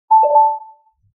Incoming Text Message Sound Effect
A clean, recognizable, and minimal alert tone designed for notifications, apps, and devices. Ideal for users who prefer a soft and discreet sound that still catches attention without being intrusive.
Incoming-text-message-sound-effect.mp3